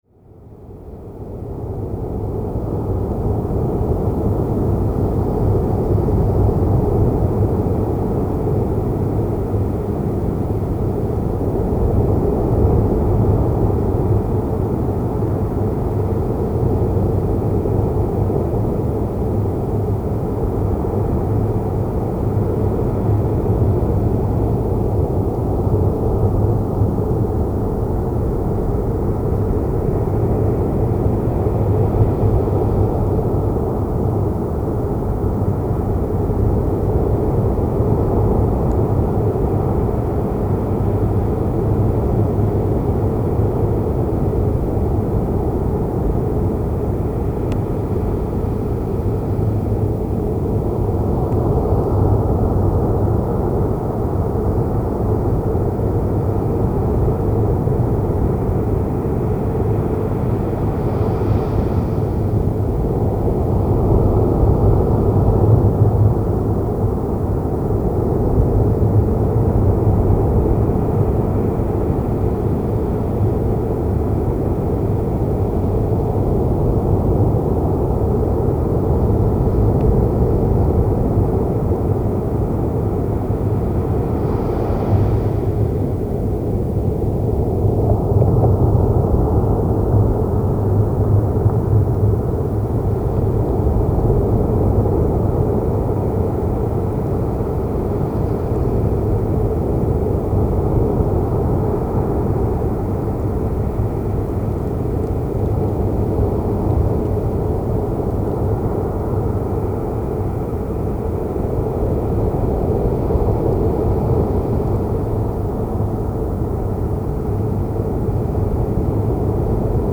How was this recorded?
Location Study of Storm and Waves at the East-Coast from Germany 2007 Unprocessed Underground Recording with Sennheiser Mkh 416 P, Windshield and Marantz PMD 660. Recorded in Boltenhagen, Coast, East-Germany in Spring 2007